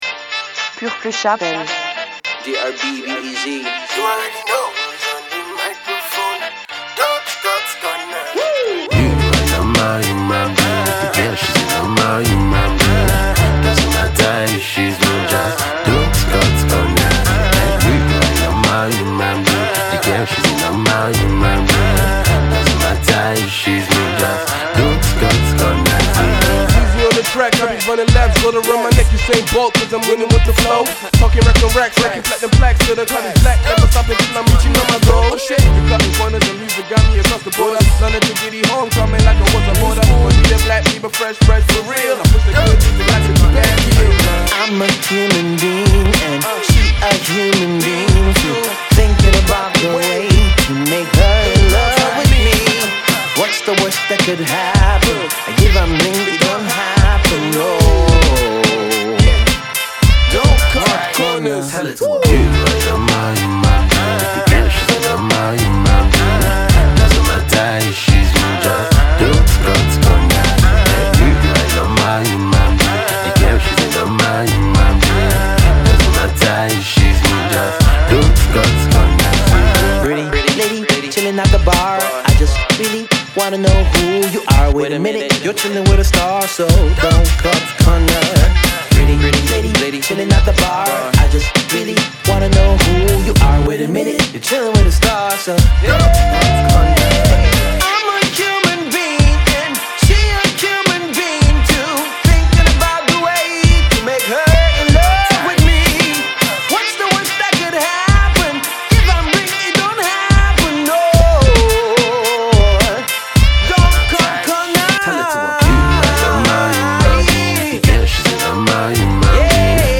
paints a beautiful soundscape